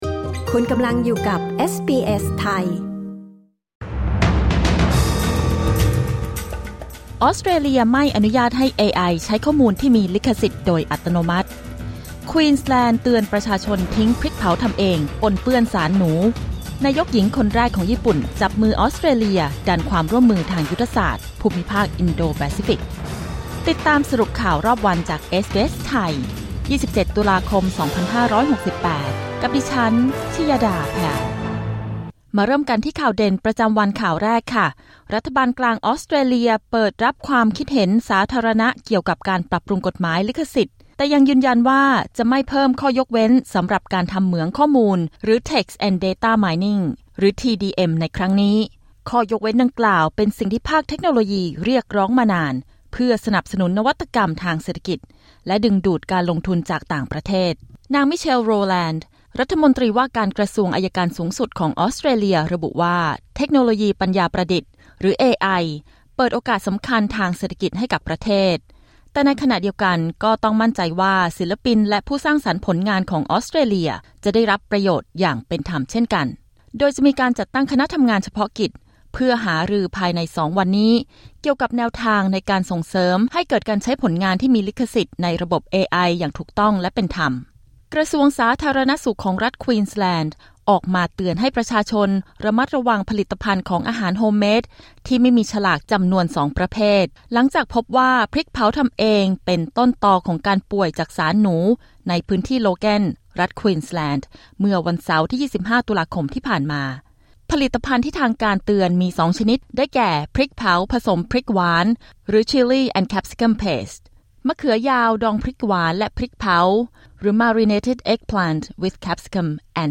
สรุปข่าวรอบวัน 27 ตุลาคม 2568